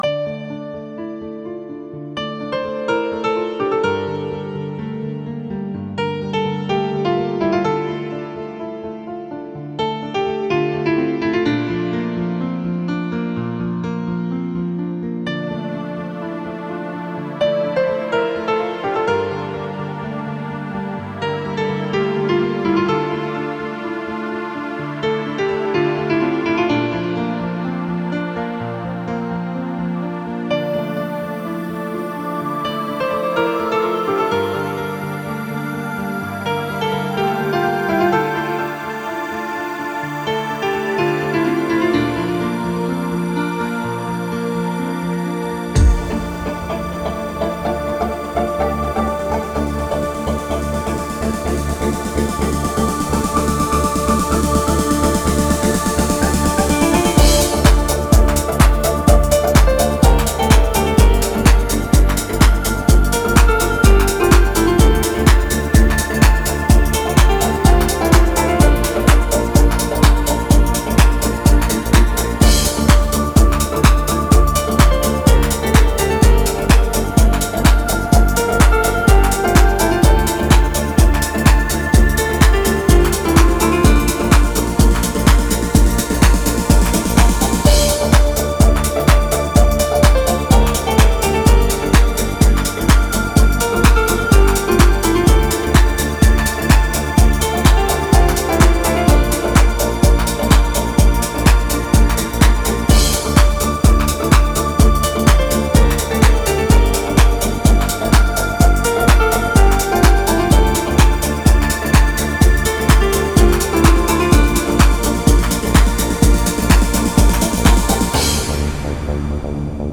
Instrumental.